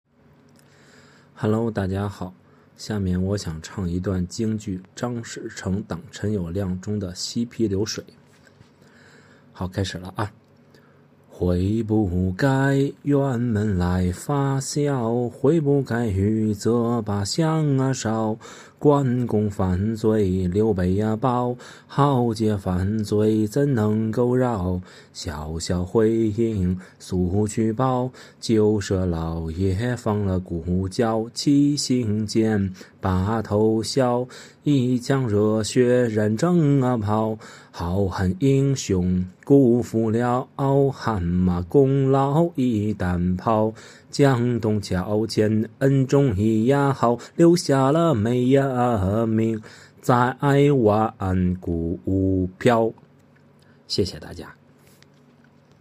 获奖曲目：京剧《挡谅》
节奏紧凑的板眼如骤雨急落，配合利落的吐字，似战场上急促的战鼓，一下下敲击在听众的心弦之上，瞬间将人带入金戈铁马的疆场。